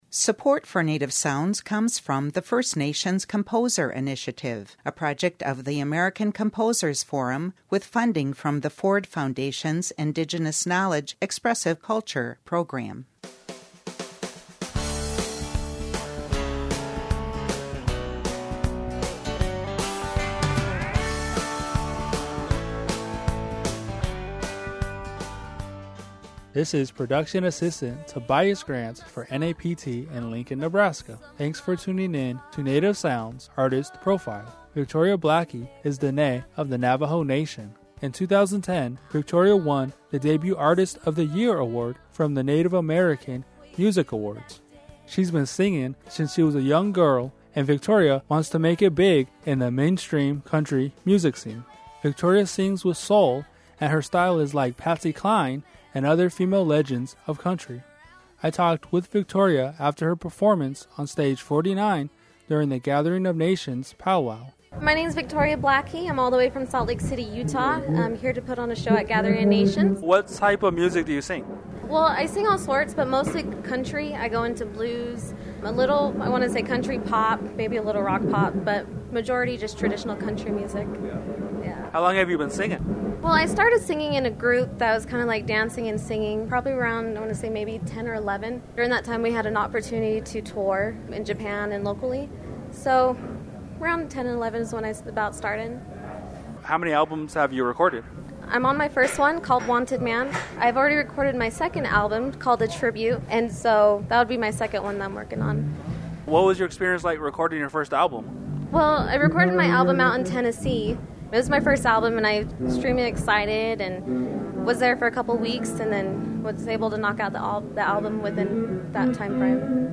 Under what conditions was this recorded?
after her performance on Stage 49 during the Gathering of Nations Powwow